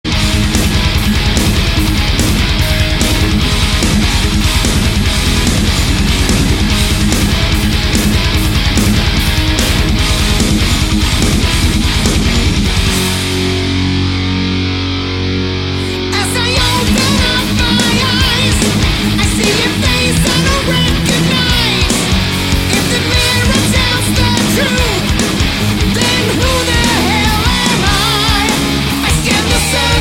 solid epic 11 pure heavy metal songs
Songs throughout the album are well mixed and composed.